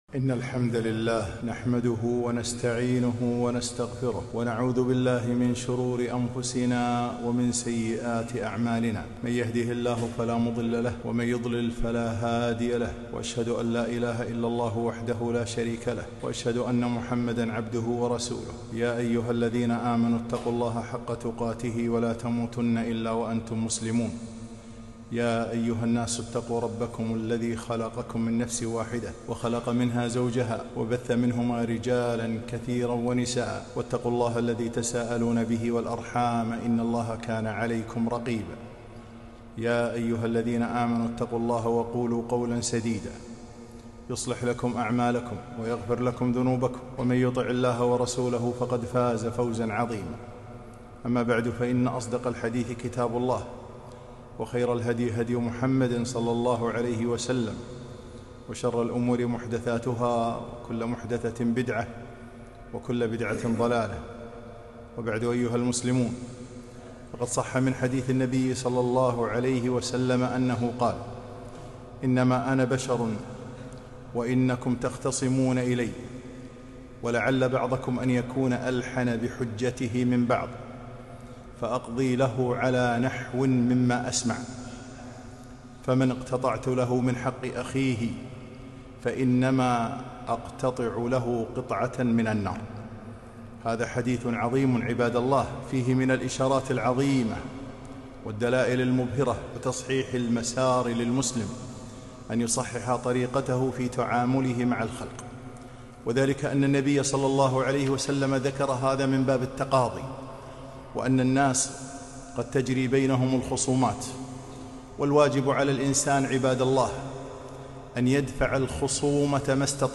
خطبة - الحذر من تجاوز الحد في العلاقات